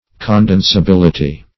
Condensability \Con*den`sa*bil"i*ty\, n. Capability of being condensed.
condensability.mp3